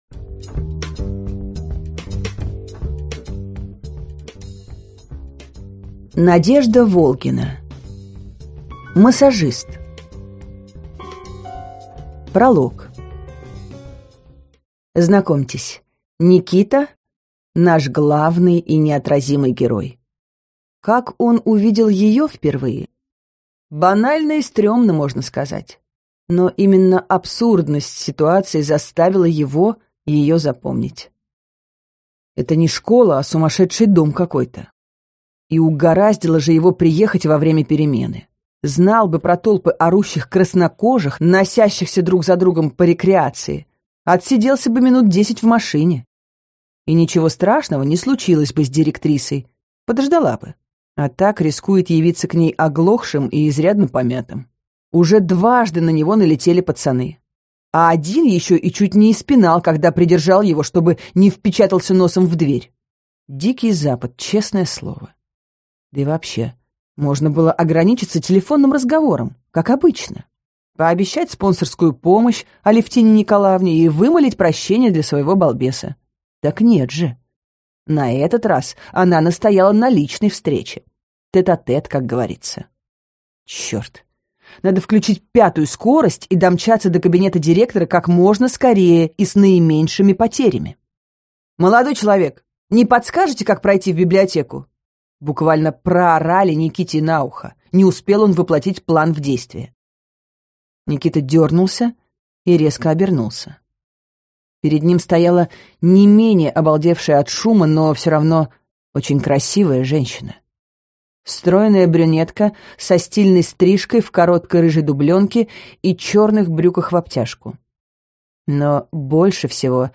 Аудиокнига Массажист | Библиотека аудиокниг